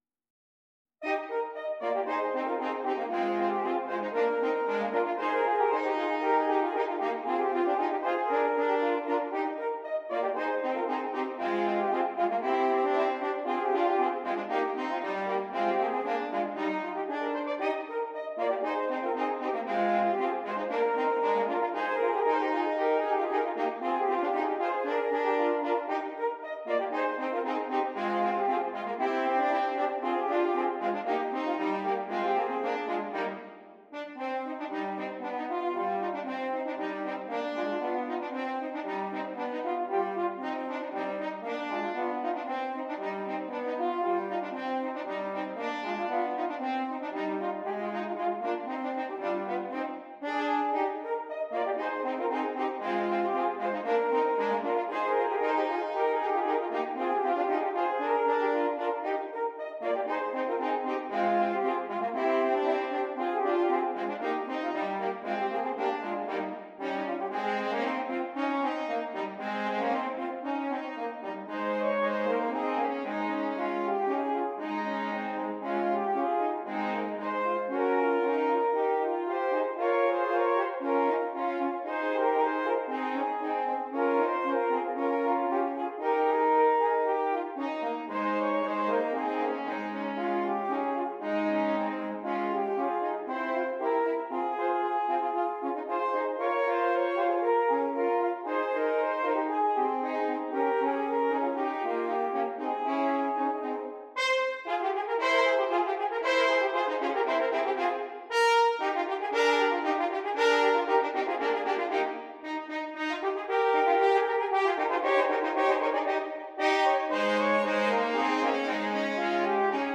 3 Horns